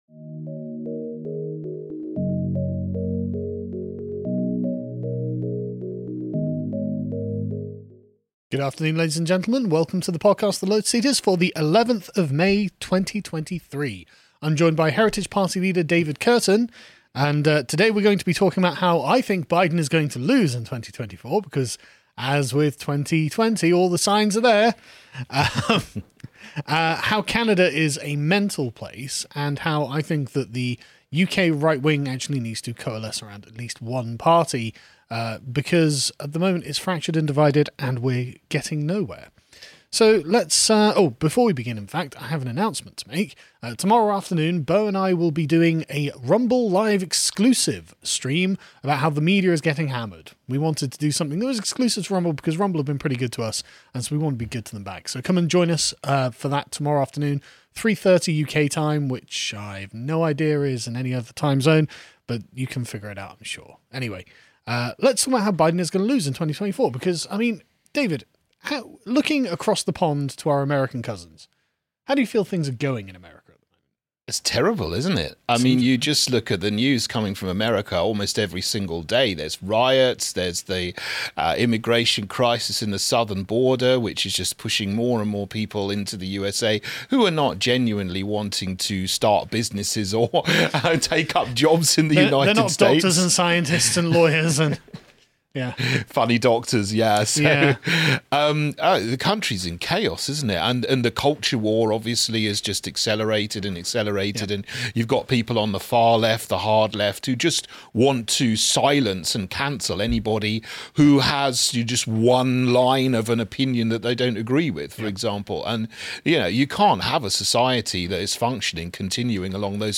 Heritage Party leader David Kurten joins Carl to discuss how the signs show that Biden is going to lose in 2024, why Canada is just an insane place, and why the UK needs a coherent right-wing opposition.